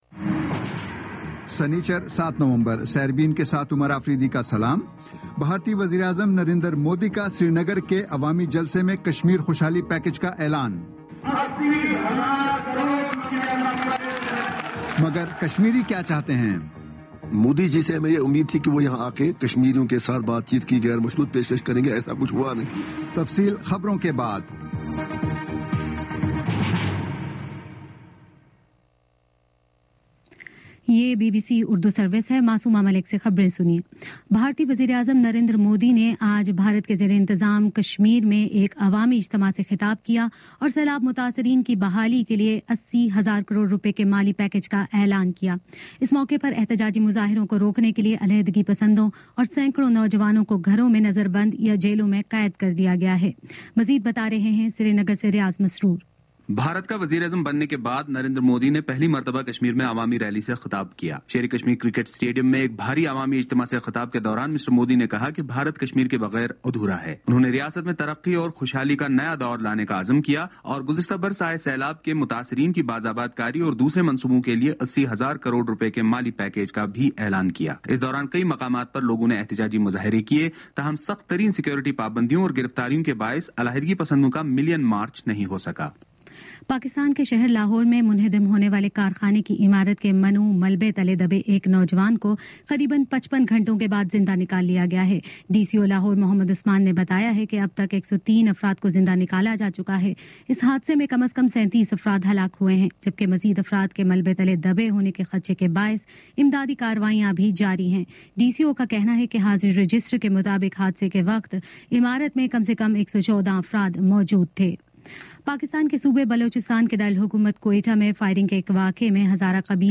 سنیچر 07 نومبر کا سیربین ریڈیو پروگرام